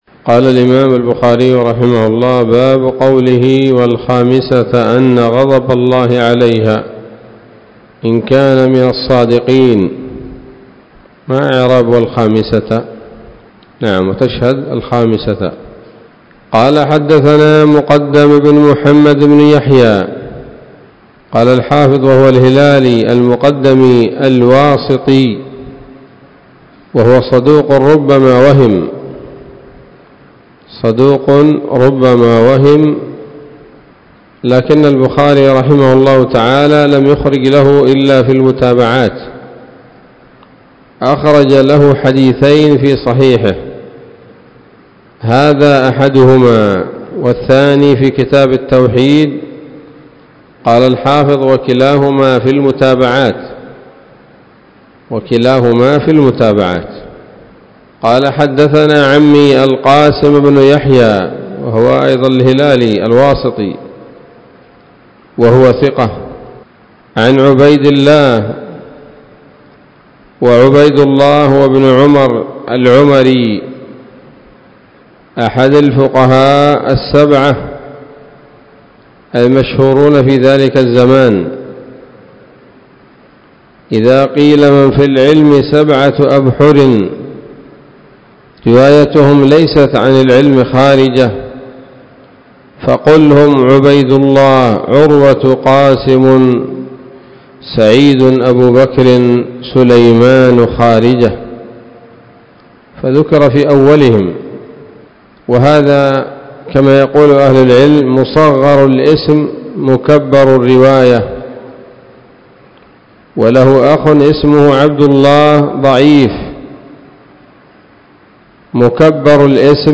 الدرس الحادي والثمانون بعد المائة من كتاب التفسير من صحيح الإمام البخاري